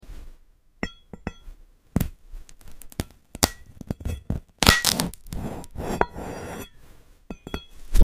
Glass Burger 🍔 ASMR Cutting sound effects free download